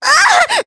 Cleo-Vox_Damage_jp_02.wav